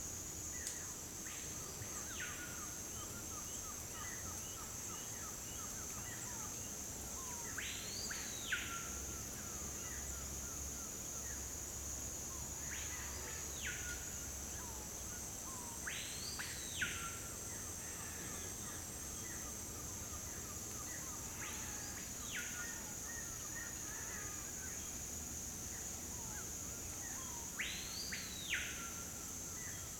rainforest
bird-voices